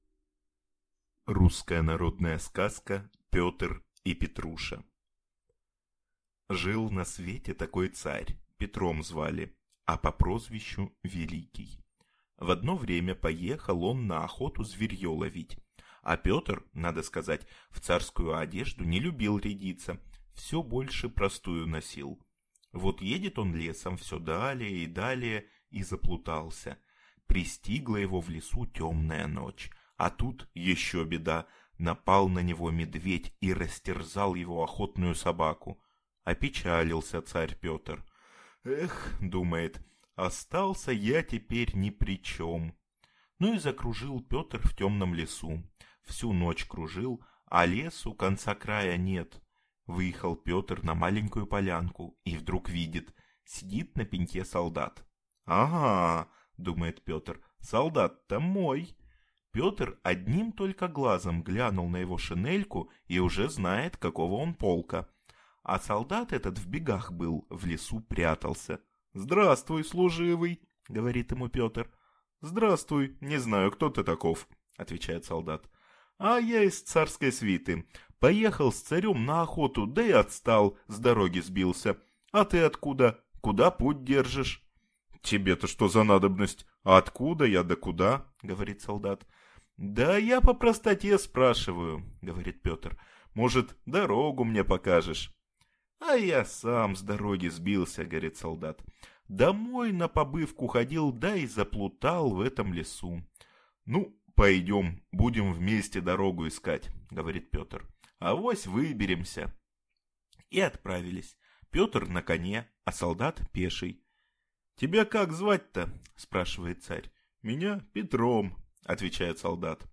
Аудиокнига Пётр и Петруша | Библиотека аудиокниг